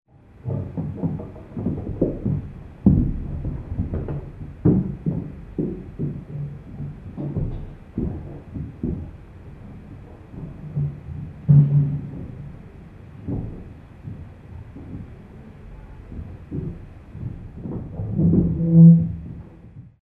Ruido de los vecinos del piso superior
Sonidos: Acciones humanas
Sonidos: Hogar